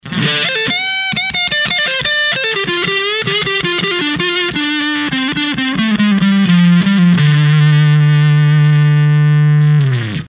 1N914を基準にしますとT4148は硬い感じというか
スッキリした感じになりますね。